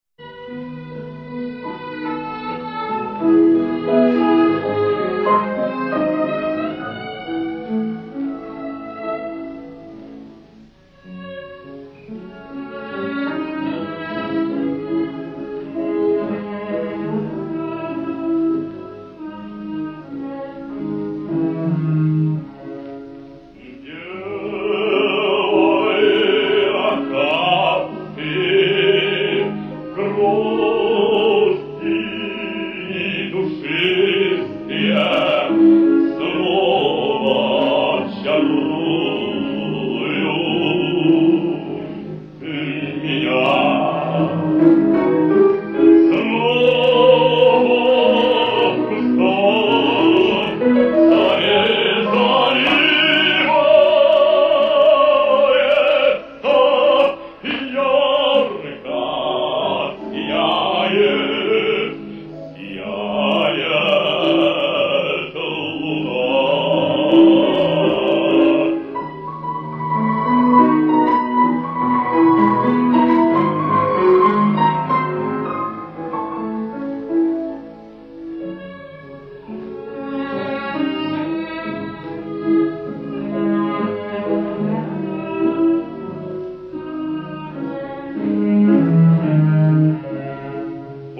Фрагмент романса